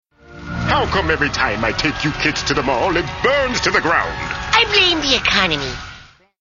Category: Television   Right: Personal
Tags: The Grim Adventures of Billy and Mandy The Grim Adventures of Billy and Mandy clips Grim & Evil Grim & Evil clips Cartoon